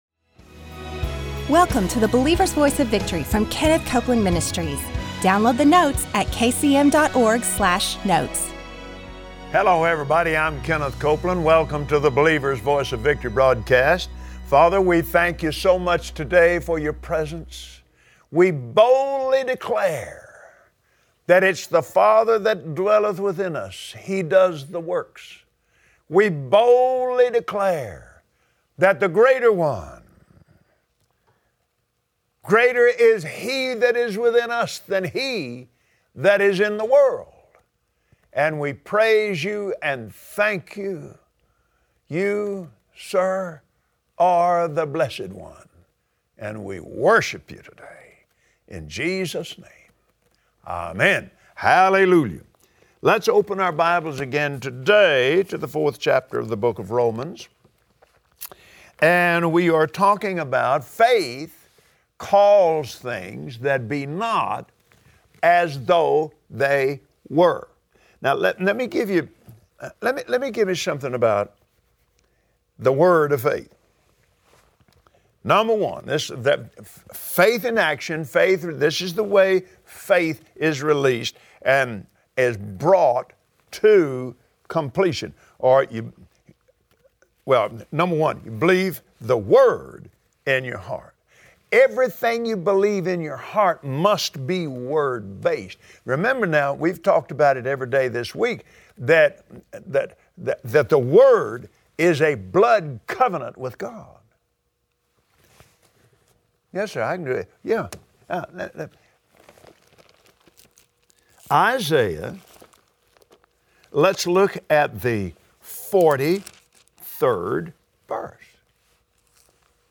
Believers Voice of Victory Audio Broadcast for Wednesday 08/10/2016 Do you need answers about faith and how it works? Join Kenneth Copeland today on the Believer’s Voice of Victory, as he explains how to put your faith into action. When you do things God’s way, you get His results.